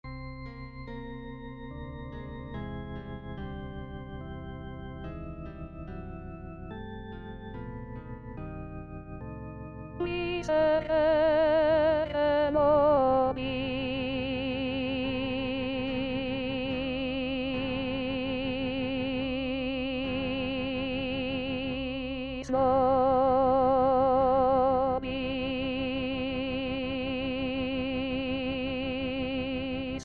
Chanté: